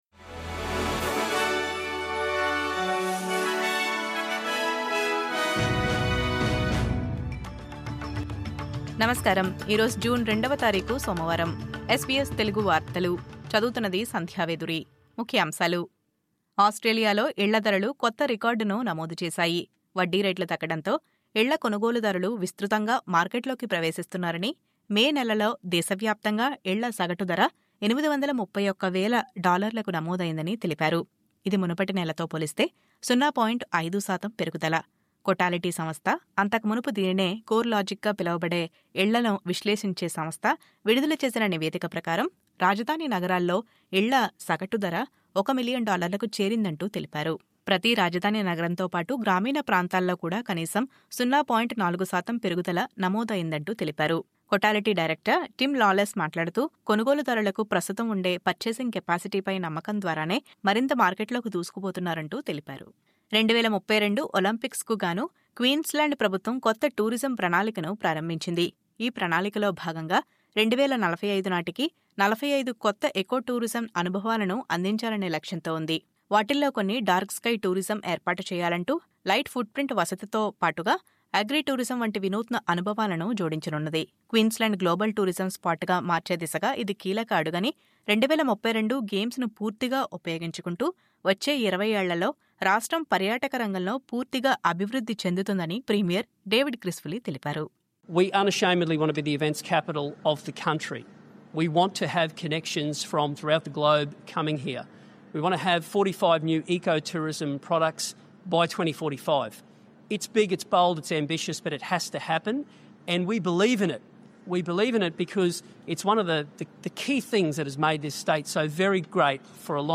SBS తెలుగు వార్తలు..